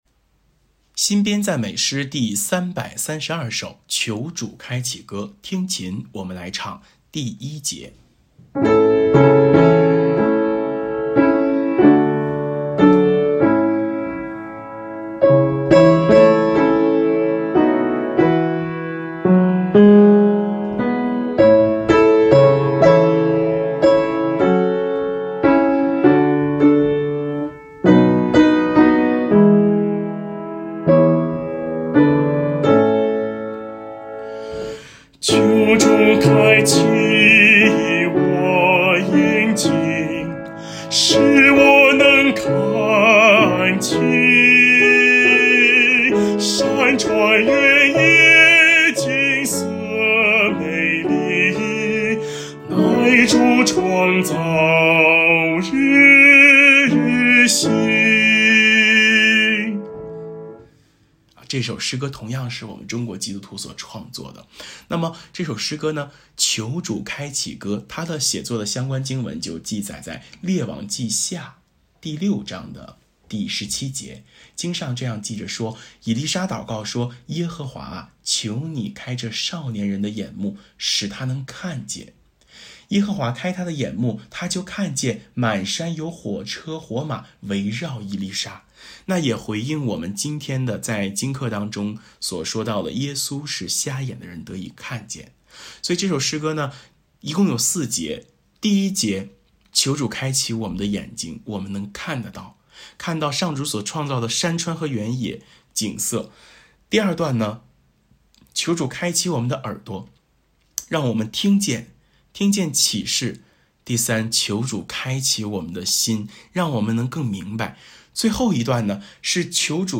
【颂唱练习】